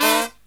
FALL HIT06-R.wav